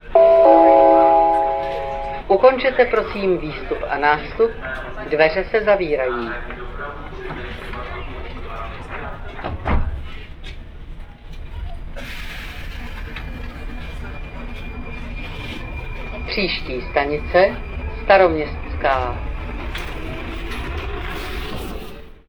9. 안내 방송
"''Ukončete, prosím, výstup a nástup, dveře se zavírají''" ("출구와 승차를 끝내주세요, 문이 닫힙니다") 와 같이 문이 닫힐 때 공공 주소 시스템을 통해 나오는 이 안내 방송은 많은 관광객들에게 프라하의 상징이 되었으며, 많은 여행객들이 처음으로 듣는 명확한 체코어 구절일 것이다.